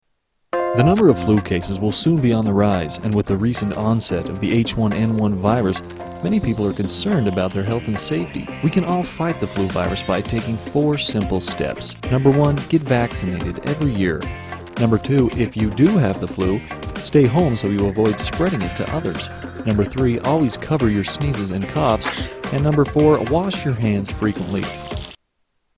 Tags: Media Flu PSA's Flu Public Service Announcements H1N1